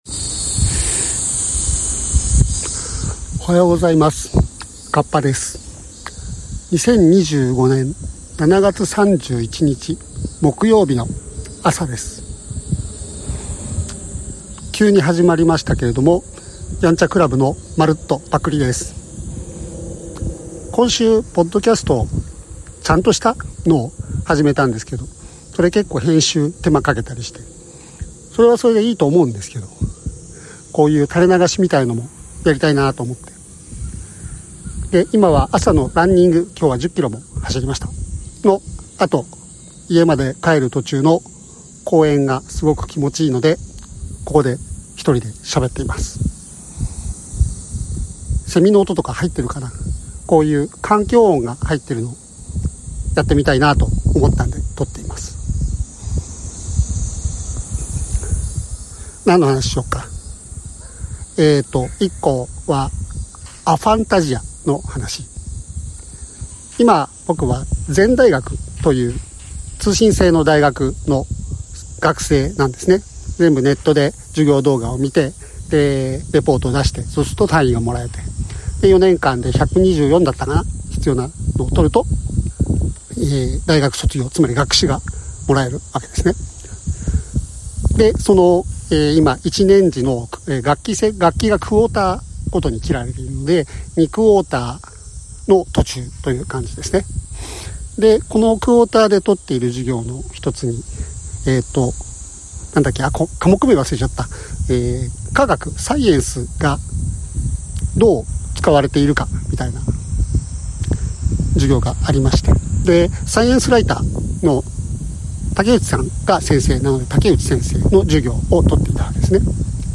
風切音があるので音量にご注意ください。次までにウインドスクリーン用意します。
Audio Channels: 1 (mono)